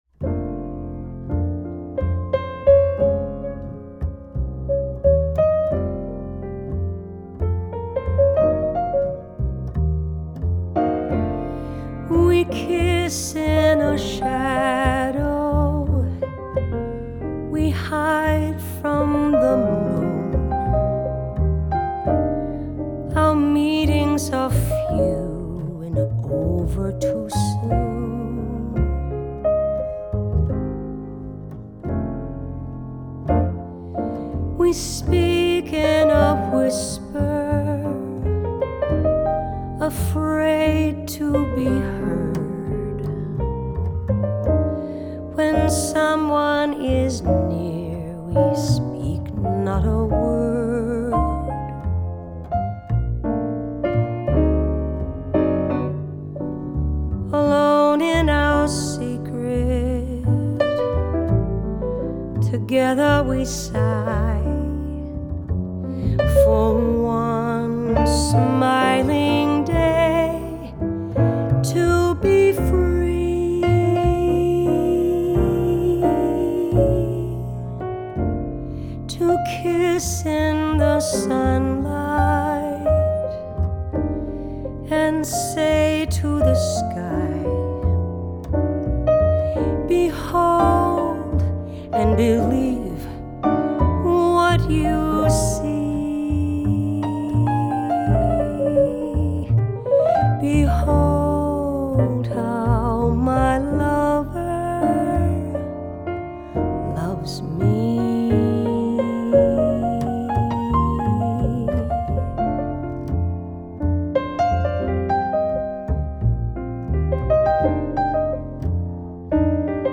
jazz pianist
jazz bass